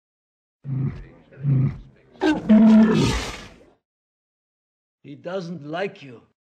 ―Ponda Baba and Dr. Evazan, to Luke Skywalker — (audio)
A walrus supplied his vocalizations, although Tim Veekhoven and Mark Newbold, the authors of the Star Wars Blog entry "Drawing from the Present: Familiar Creatures in a Galaxy Far, Far Away," could not confirm whether the walrus was Petula.[12]